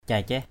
/caɪ-cɛh/ (cv.) taitaih =t=tH (đg.) tập đi = exercer à marcher. anâk ranaih caicaih thau nao anK r=nH =c=cH E~@ _n< trẻ nhỏ tập tễnh bước đi.